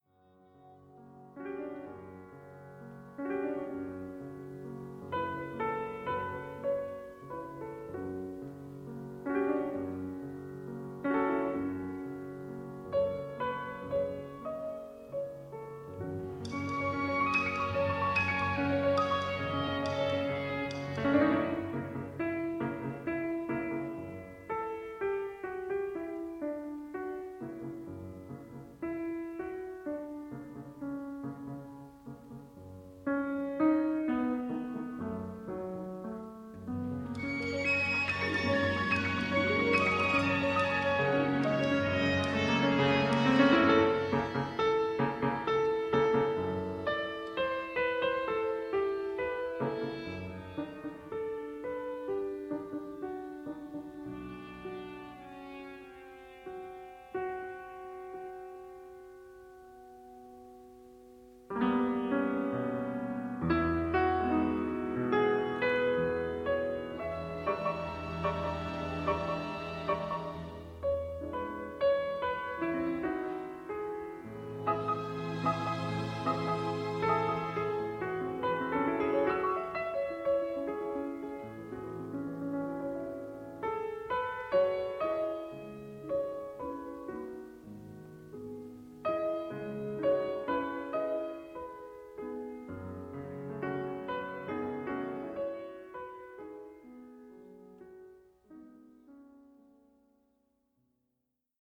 Концерт для ф-но Gdur. Исп. Артуро Бенедетти Микеланджели, орк. “Philharmonia”, дир. Этторе Грачис: